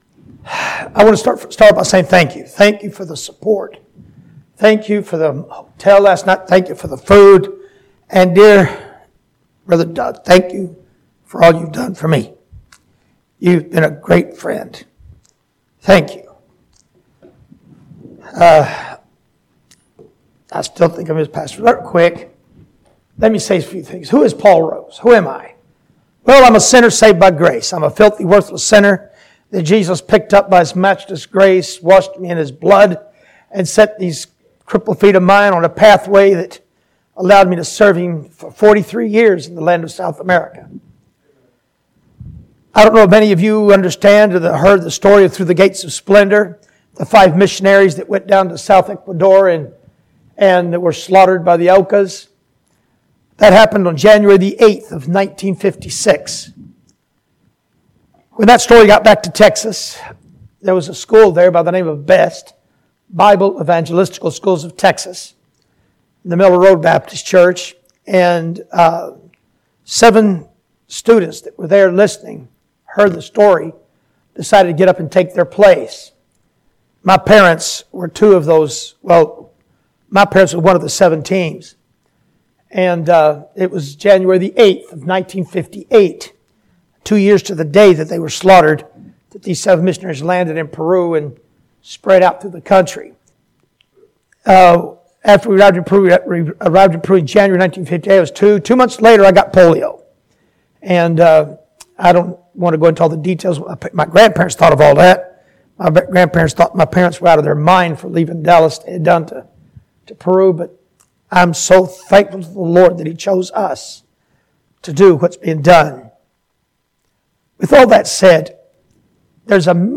This sermon from Psalm 65 studies the principles of missions and challenges the believer in their walk with the Lord.